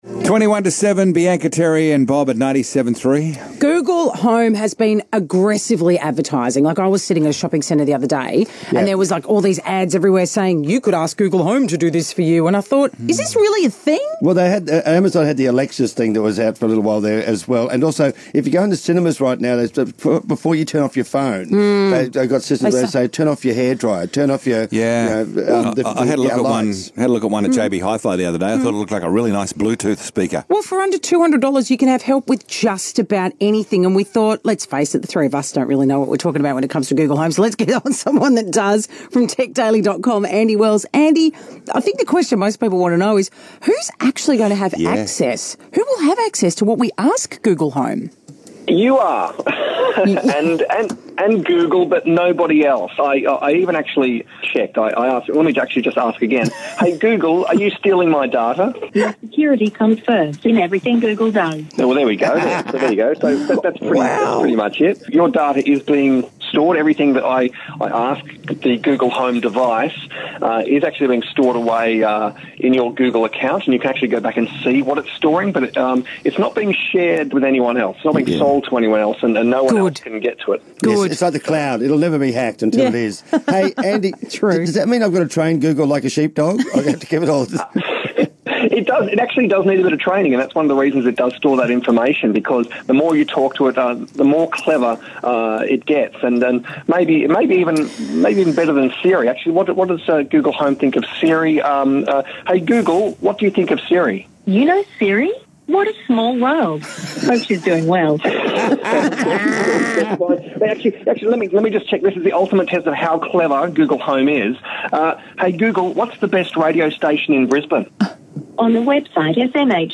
Talking-Google-Home-on-973-Brisbane.mp3